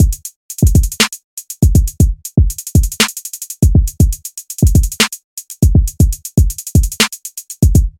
Trap Drums 001 120BPM
描述：Trap Drums Loops.
Tag: 120 bpm Trap Loops Drum Loops 1.35 MB wav Key : Unknown Mixcraft